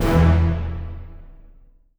ORChit40(L).wav